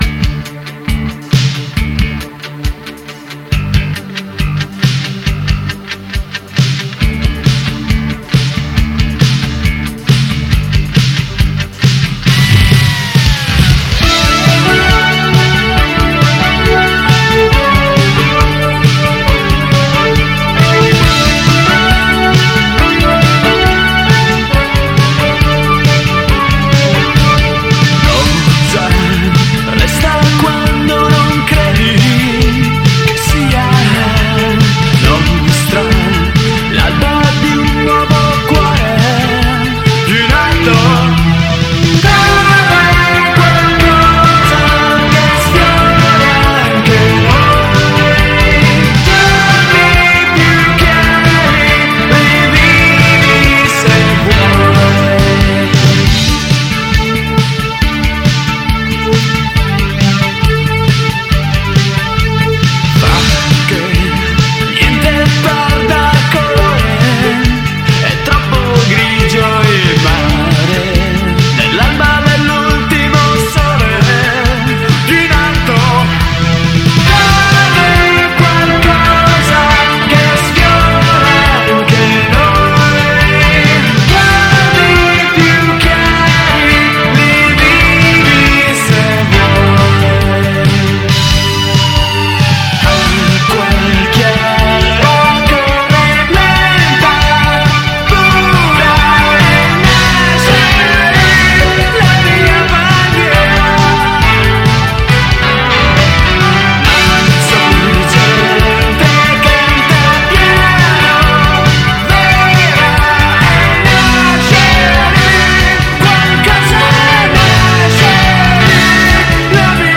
due gradevoli techno-pop in perfetto stile anni ’80